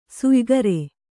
♪ suygare